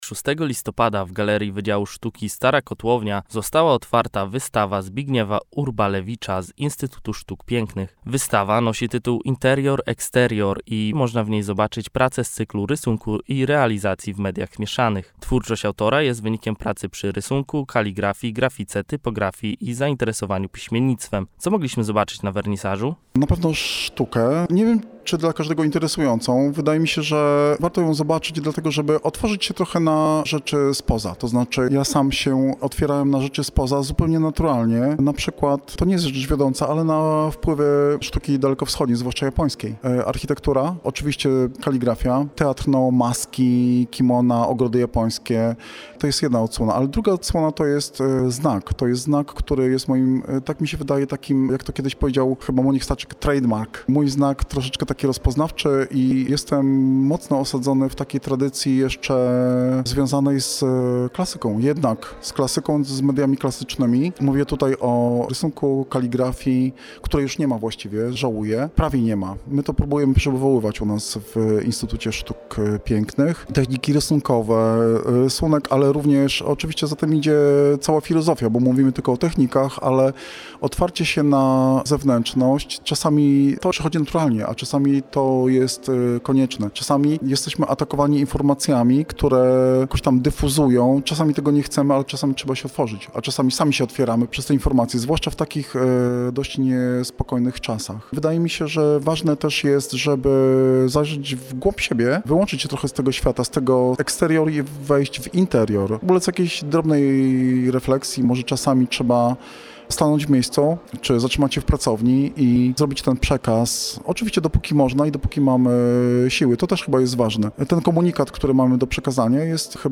Wernisaż odbył się w środę.
Wernisaż odbył się w środę 6 listopada.